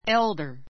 elder éldə r エ る ダ 形容詞 年上の ✓ POINT old の比較 ひかく 級の1つだが, 主に兄弟・姉妹 しまい の関係での年上を示す.